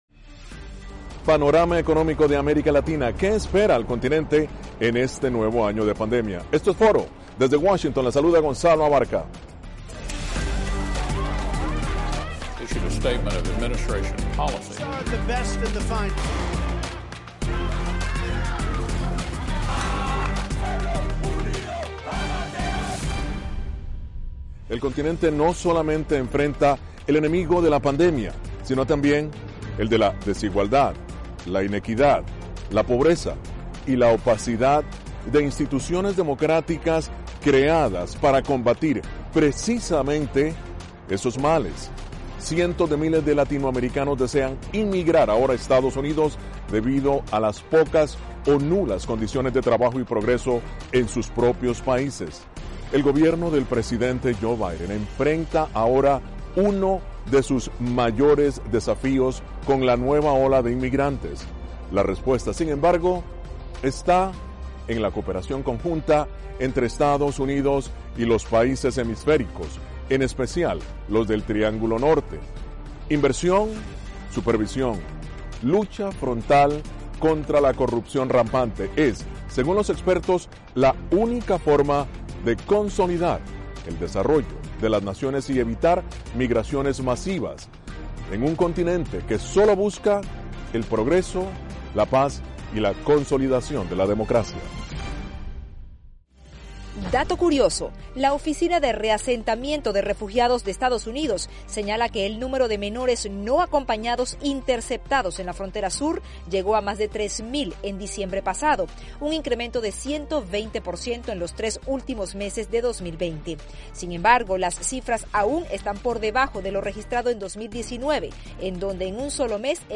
Foro: Perspectivas económicas 2021